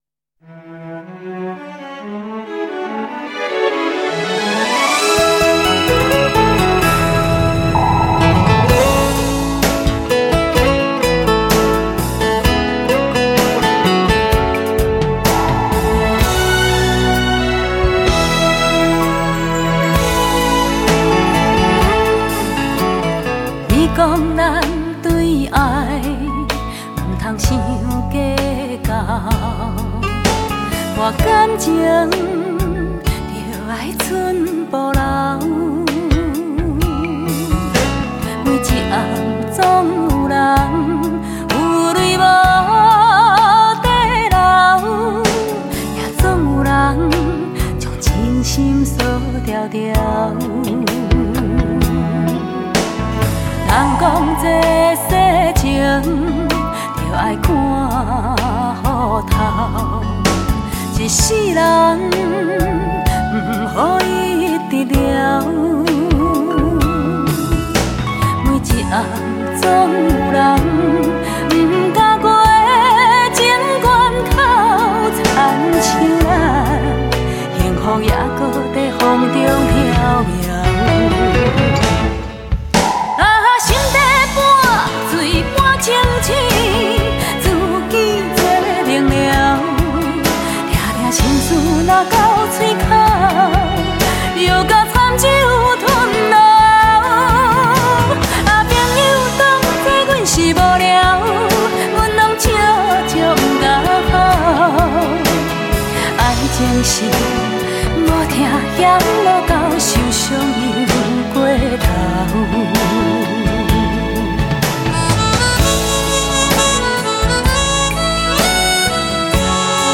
闽南语歌曲一般纯朴通俗，感情丰富，旋律大多都带着入骨的悲怆充满韵味又易于传唱，极易引起人们的共鸣。